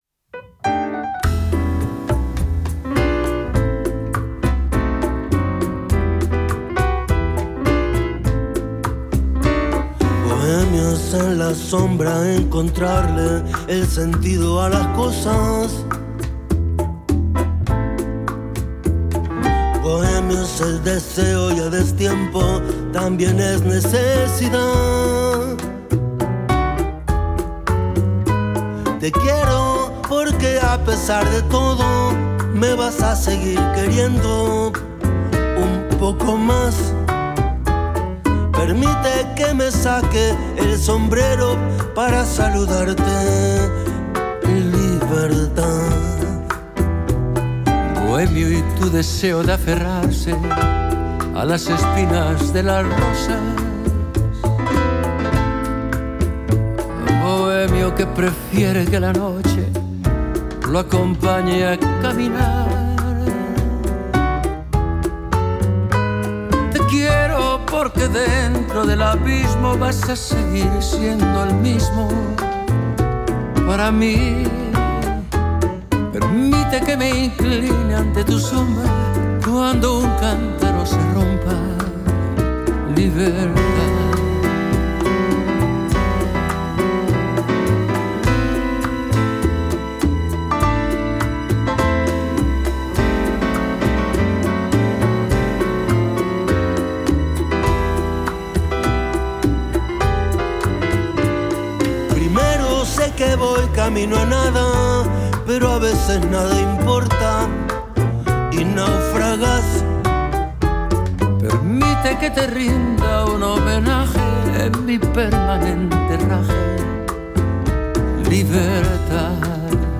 Entrevista con Andrés Calamaro
Entrevistado: "Andrés Calamaro"